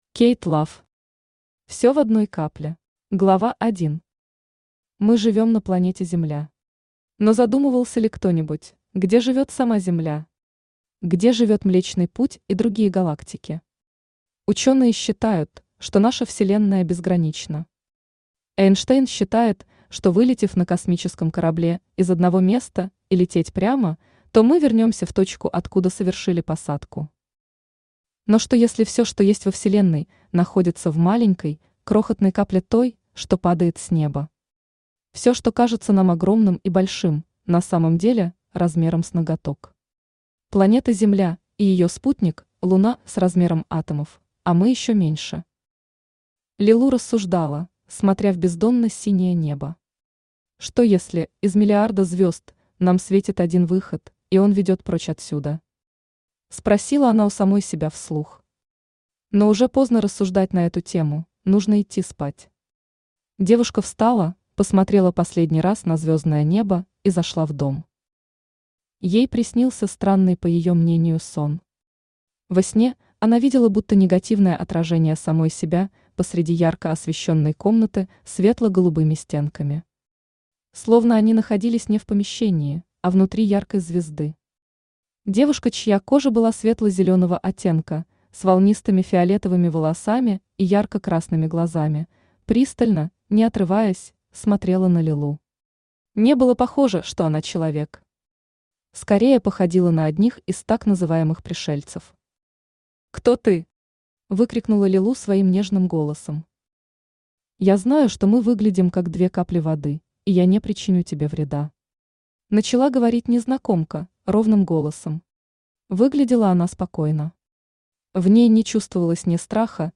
Аудиокнига Всё в одной капле | Библиотека аудиокниг
Aудиокнига Всё в одной капле Автор Кейт Лав Читает аудиокнигу Авточтец ЛитРес.